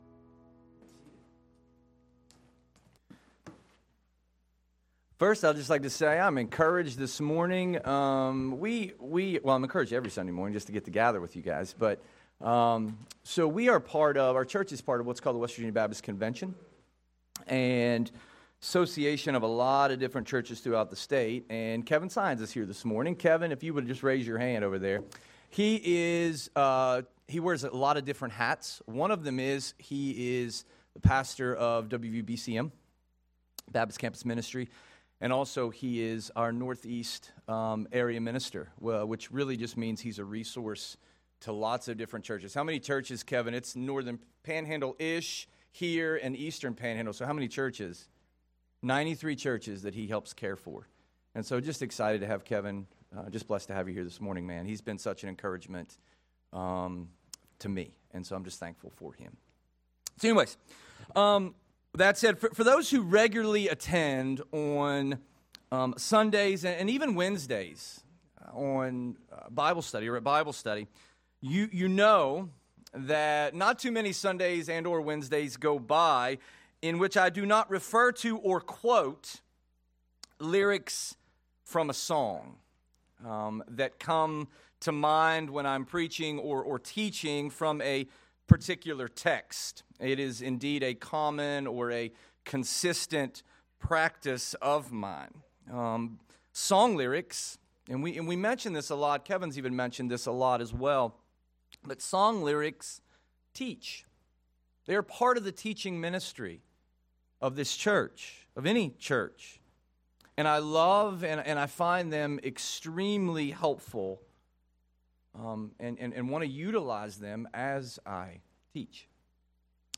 Feb 08, 2026 Because He Lives (02/08/2026) MP3 SUBSCRIBE on iTunes(Podcast) Notes Discussion Sermons in this Series 1 Corinthians 15:12-34 Loading Discusson...